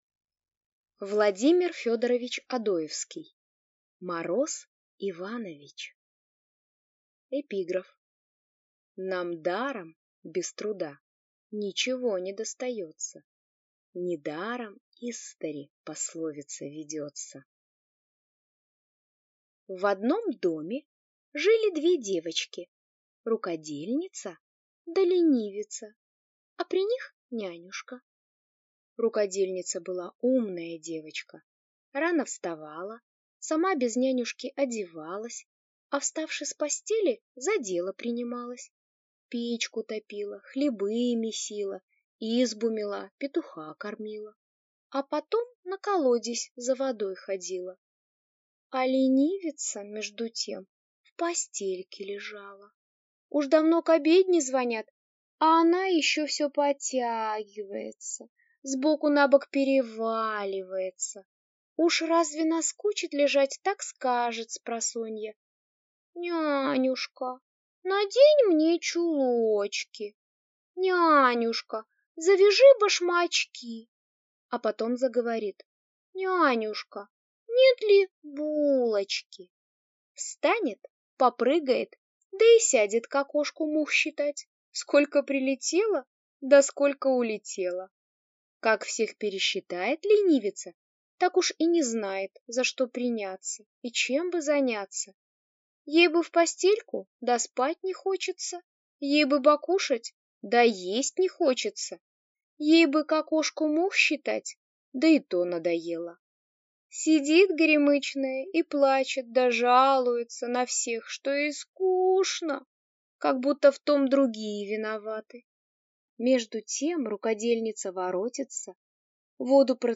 Аудиокнига Мороз Иванович | Библиотека аудиокниг